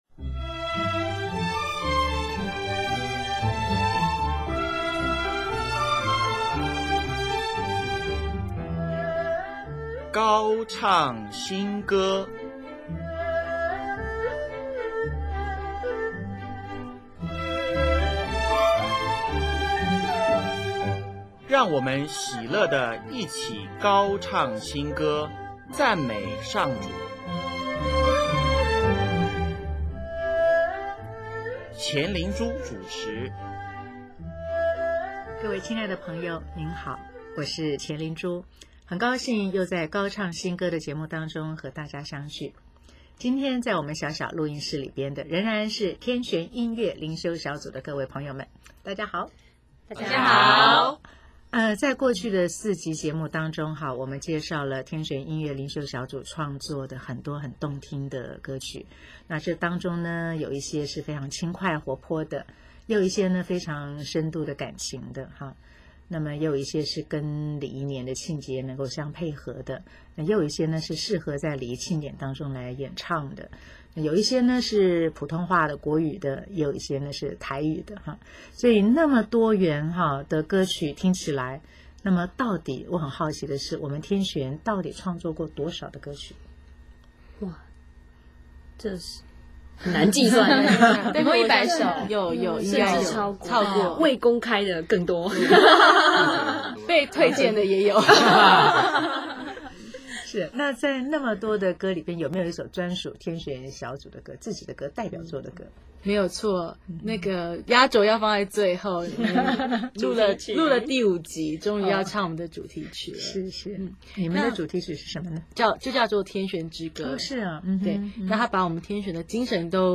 【高唱新歌】61|专访天旋音乐灵修小组(五)：唱天主教的歌